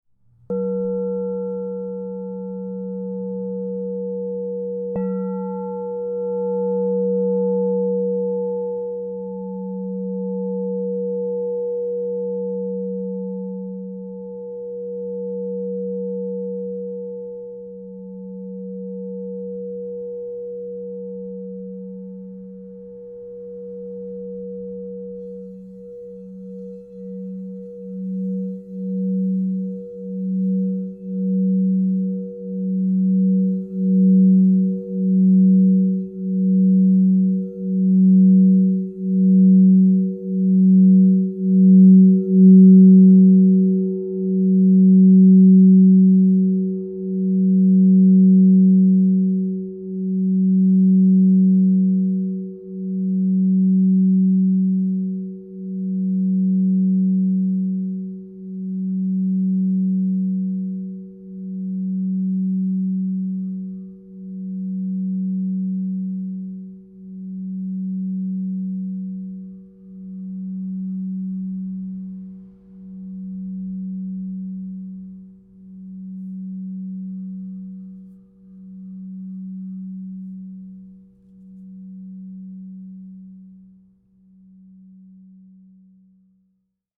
Lemurian Seed, Ebony Aura Gold Morph 12" F# +30 Crystal Tones singing bowl
Experience the harmonizing energy of the Crystal Tones® Lemurian Seed Double Alchemy 12 inch F# Positive 30 Singing Bowl, resonating at F# +30 to inspire transformation and spiritual alignment.
The expansive 12-inch size delivers rich, resonant tones that fill any space with harmonious frequencies, making it ideal for group healing sessions, personal meditation, or creating a sacred sanctuary.
F#
528Hz (+)